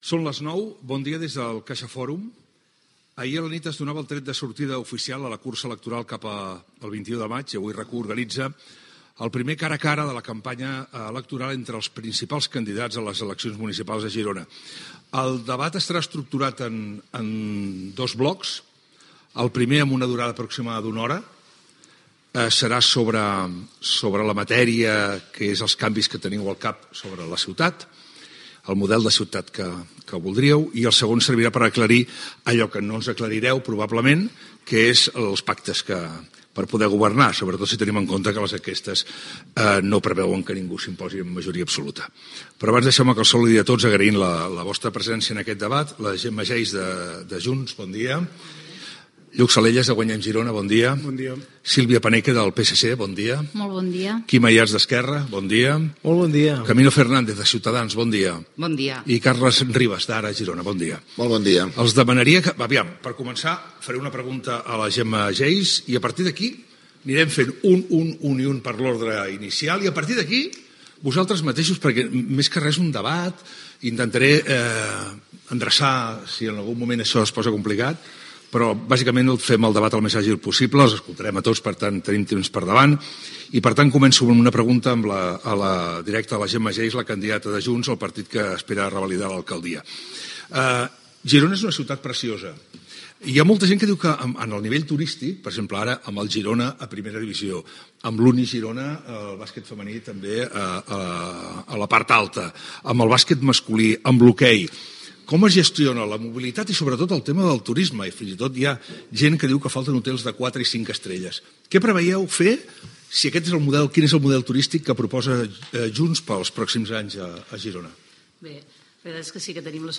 Presentació del debat a les eleccions municipals a l'Ajuntament de Girona, intervenció inicial de Gemma Geis, la cap de llista per Junts.
Informatiu
FM